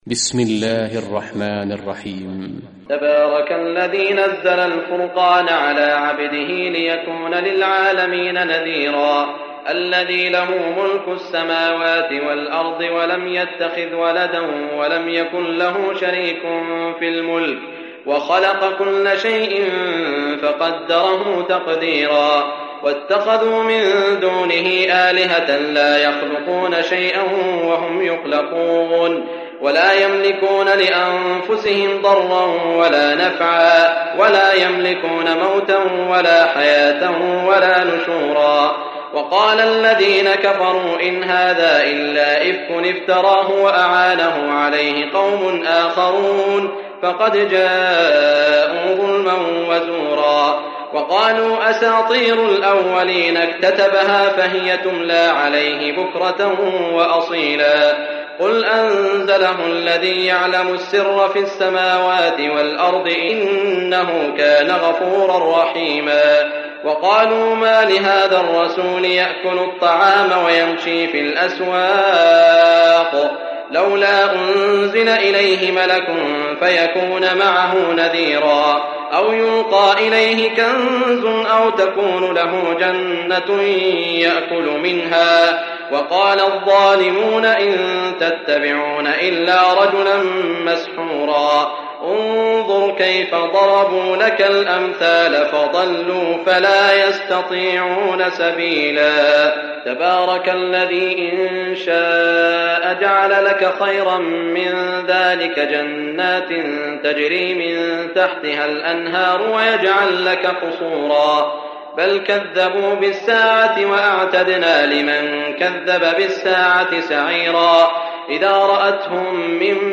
Surah Furqan Recitation by Sheikh Saud Shuraim
Surah Furqan is 25 chapter of Holy Quran. Listen or play online mp3 tilawat / recitation in Arabic in the beautiful voice of Sheikh Saud Al Shuraim.